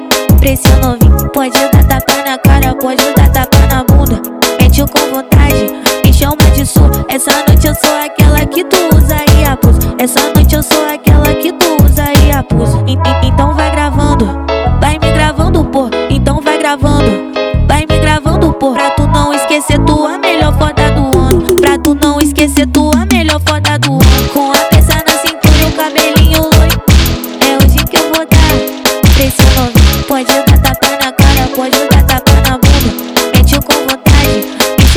Жанр: R&B / Соул / Фанк
Funk, R&B, Soul, Brazilian, Baile Funk